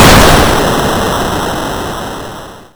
True Game Over.wav